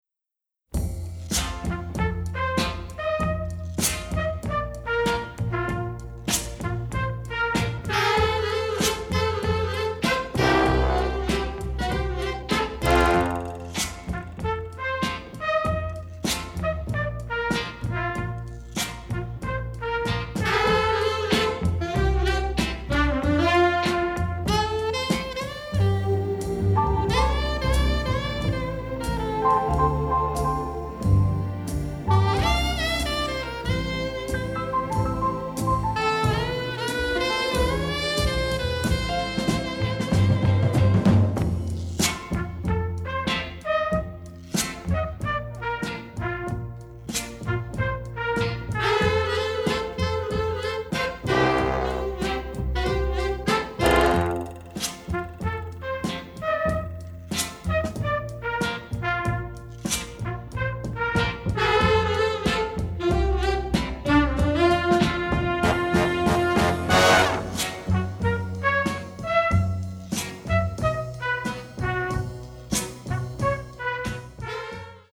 BONUS TRACKS (Mono)